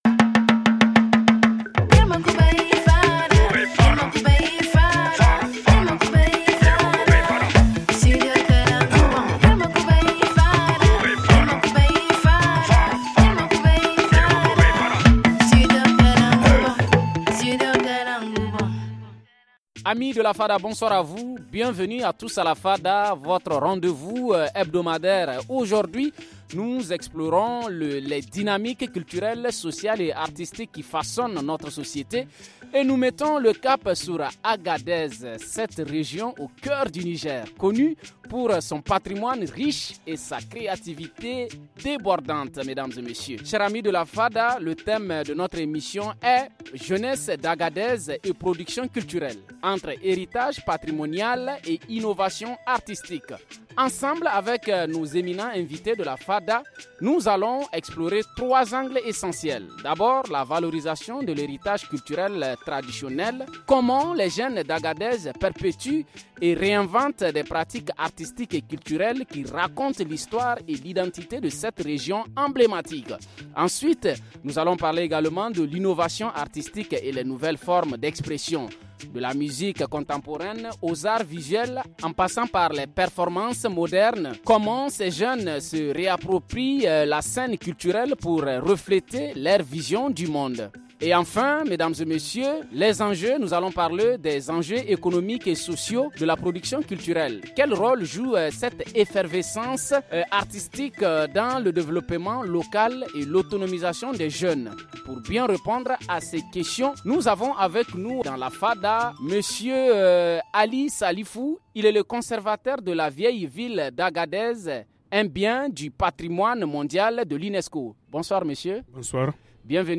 Pour enrichir ce débat, nous invitons